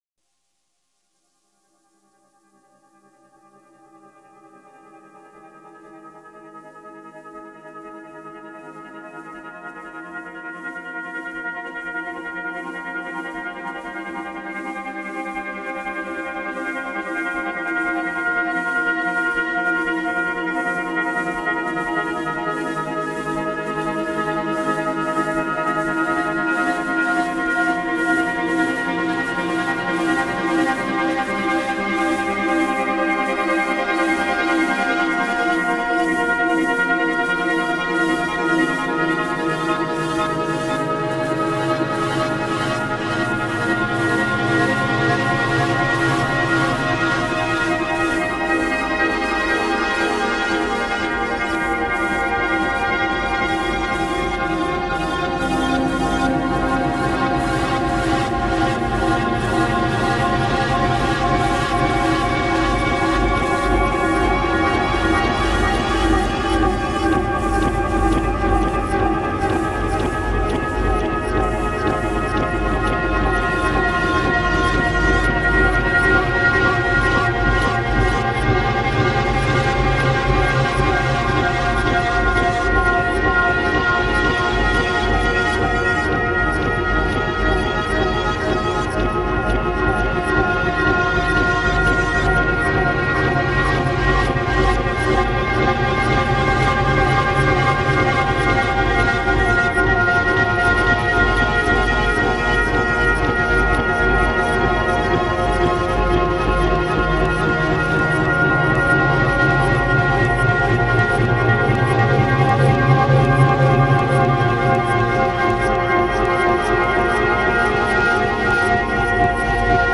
Rollright Stones pagan ritual reimagined